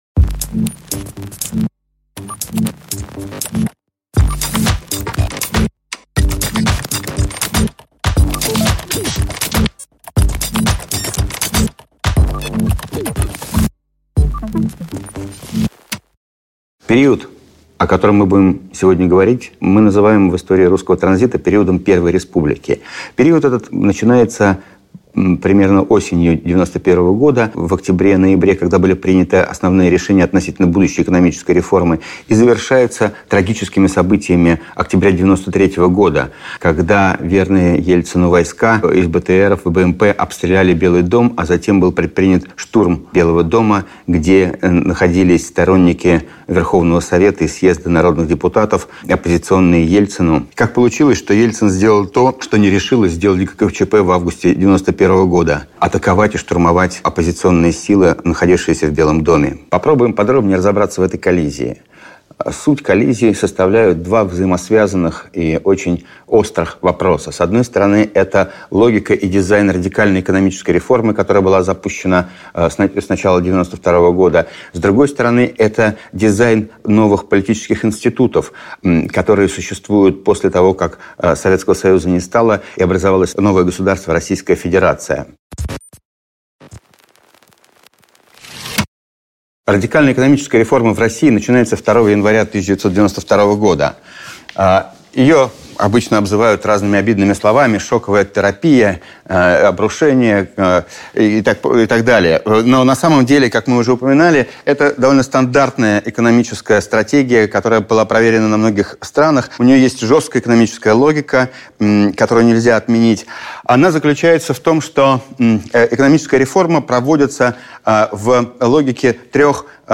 Аудиокнига Коллапс Первой республики | Библиотека аудиокниг
Прослушать и бесплатно скачать фрагмент аудиокниги